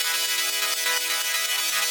SaS_MovingPad01_125-E.wav